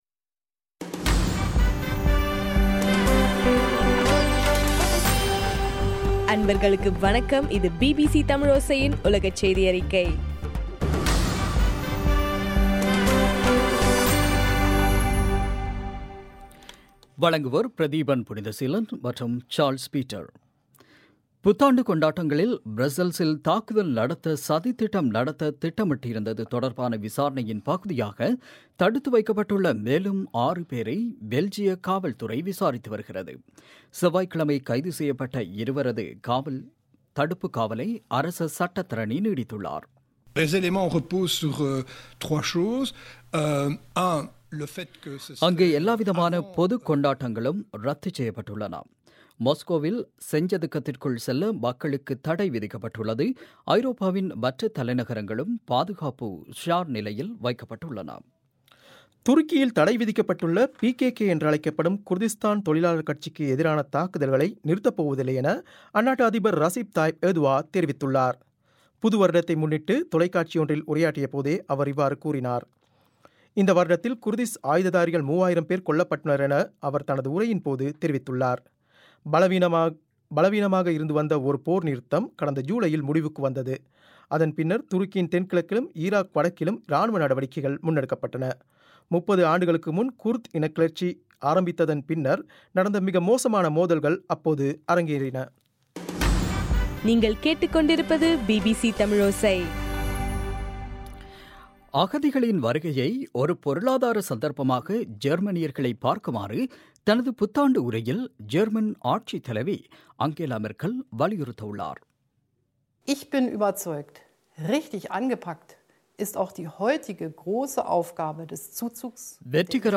டிசம்பர் 31 பிபிசியின் உலகச் செய்திகள்